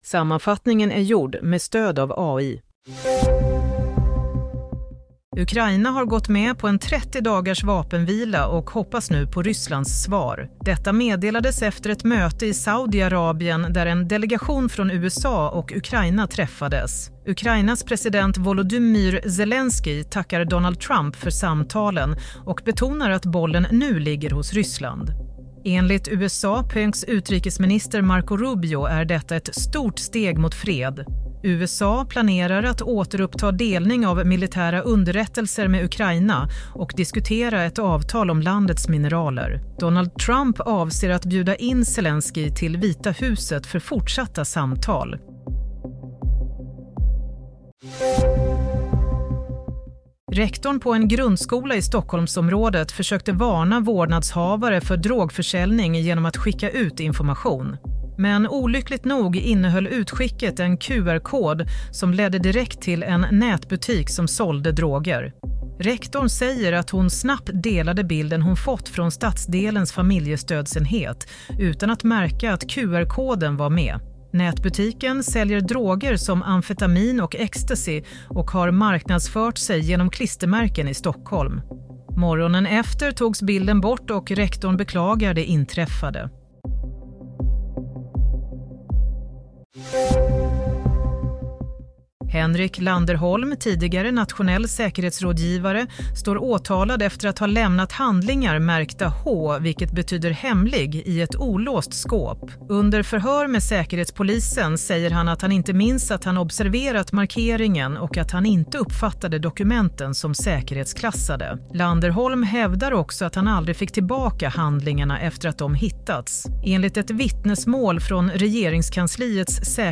Nyhetssammanfattning - 11 mars 22.00
Sammanfattningen av följande nyheter är gjord med stöd av AI.